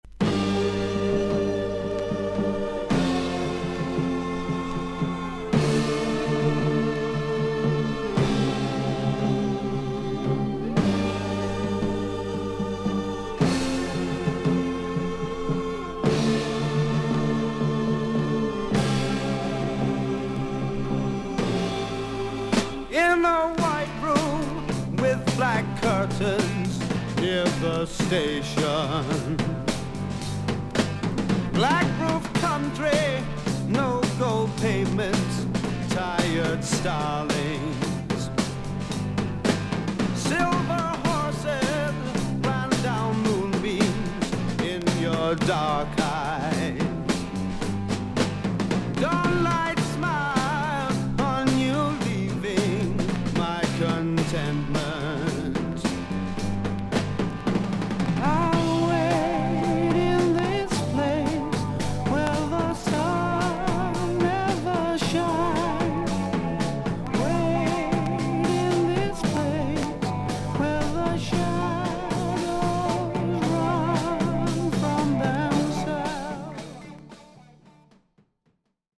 少々軽いパチノイズの箇所あり。少々サーフィス・ノイズあり。クリアな音です。
スタジオ録音とライヴを収録したLP2枚組。